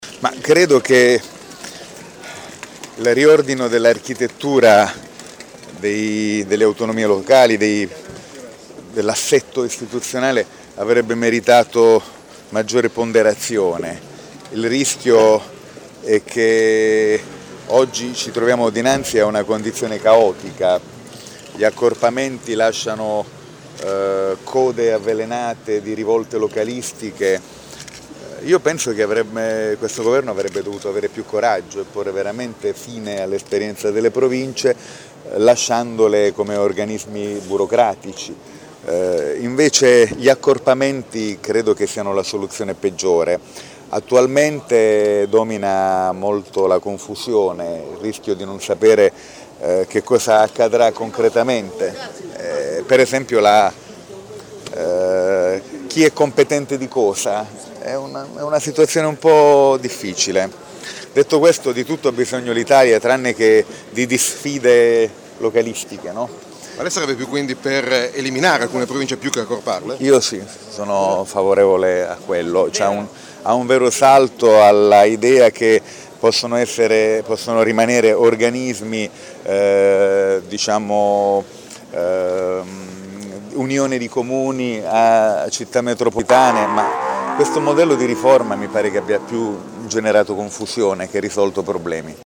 A margine dell’incontro organizzato oggi all’Ariston da Sel per le primarie del centro-sinistra, con il Presidente della Regione Puglia, Nichi Vendola, si è parlato di accorpamento delle Province, un tema particolarmente importante per l’imperiese, visto che la nostra è uno degli enti che verranno ‘annessi’ ad un’altra, Savona nella fattispecie.